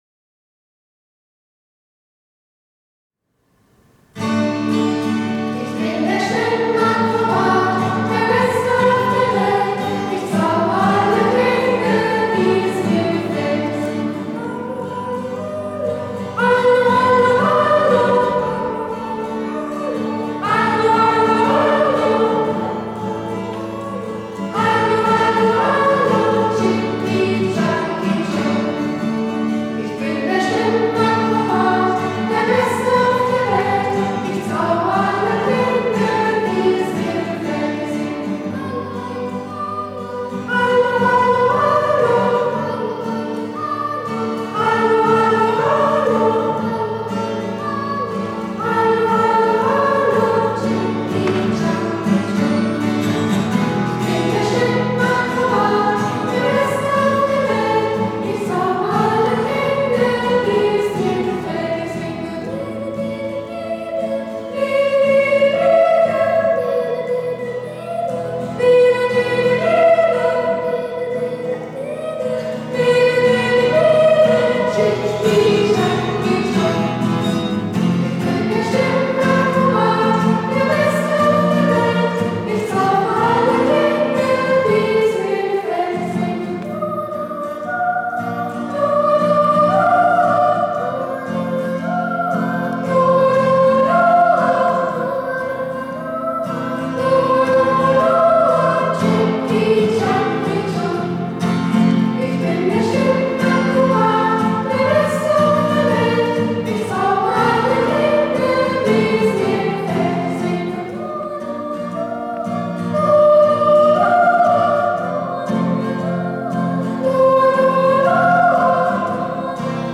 Kinderchor | Ev.-luth.